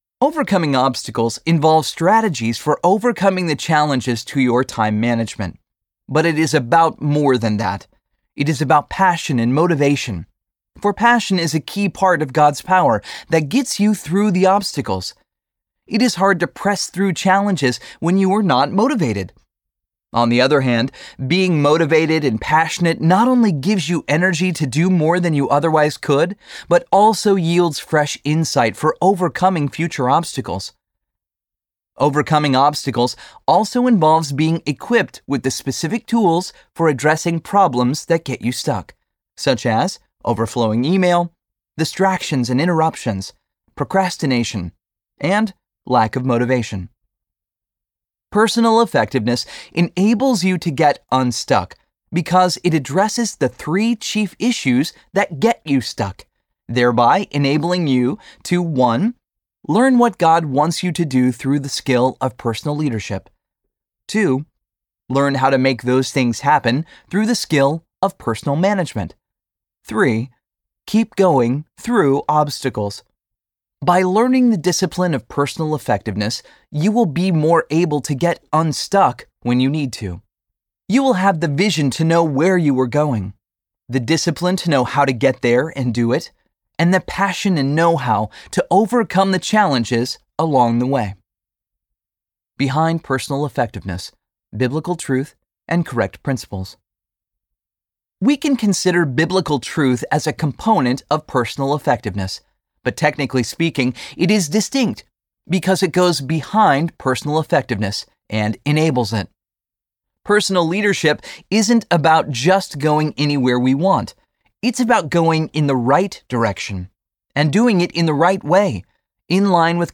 How to Get Unstuck Audiobook
Narrator
7.53 Hrs. – Unabridged